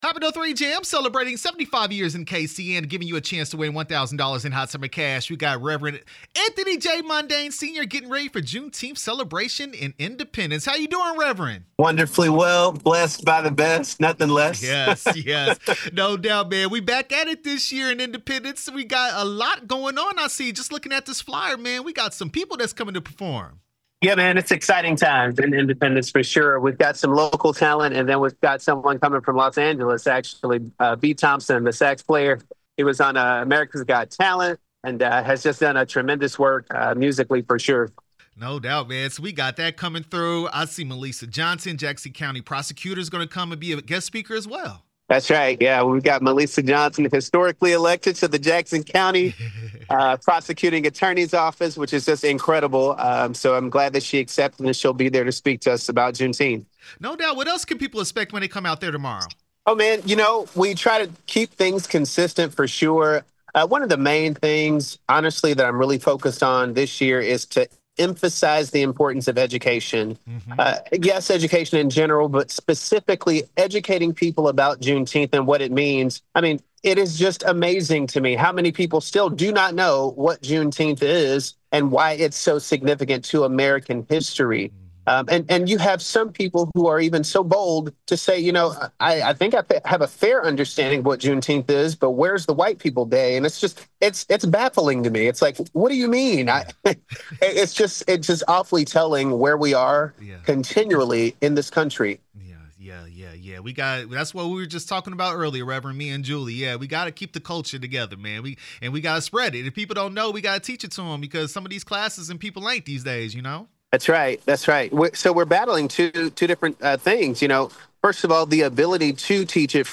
Juneteenth Independence interview 6/18/25